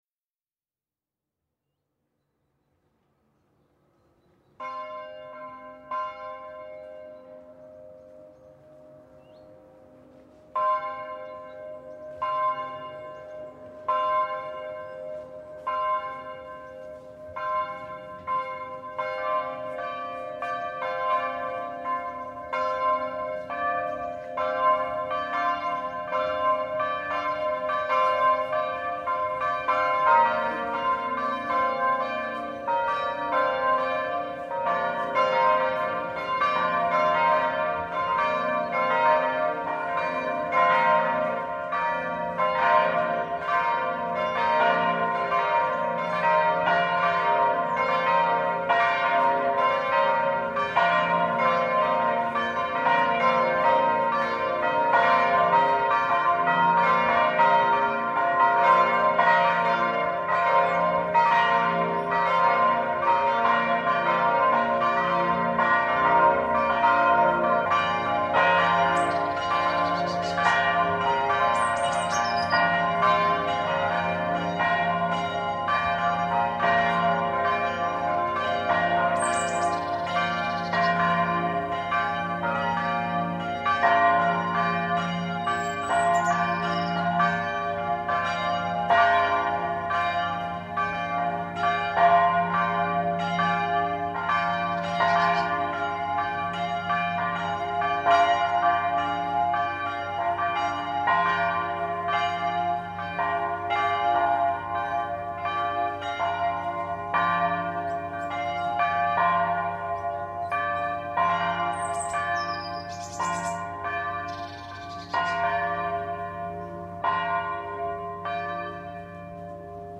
The_Bells_of_Taize.mp3